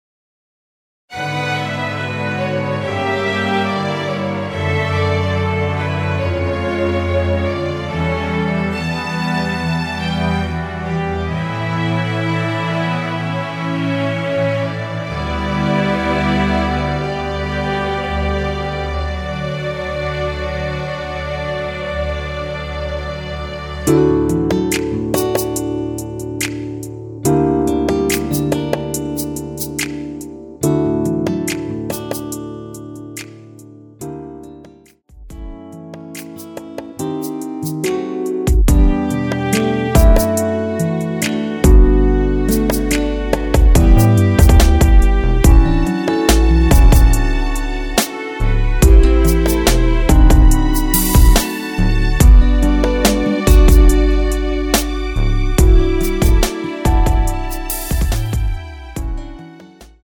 ◈ 곡명 옆 (-1)은 반음 내림, (+1)은 반음 올림 입니다.
앞부분30초, 뒷부분30초씩 편집해서 올려 드리고 있습니다.
축가 MR